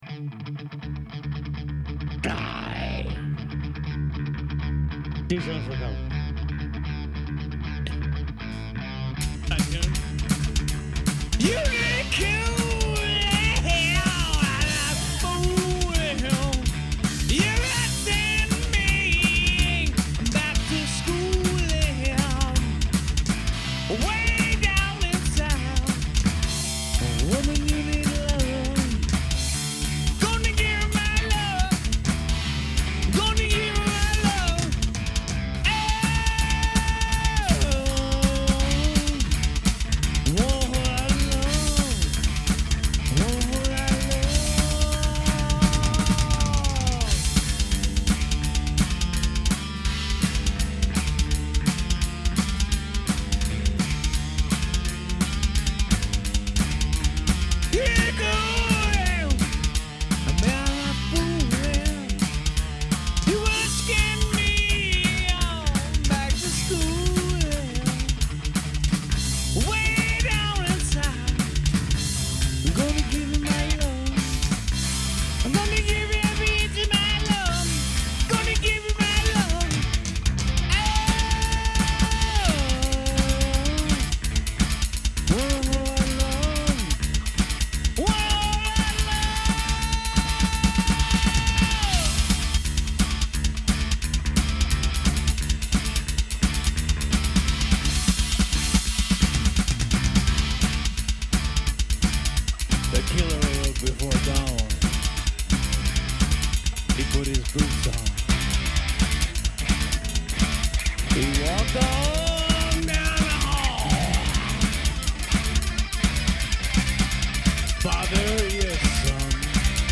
Session Takes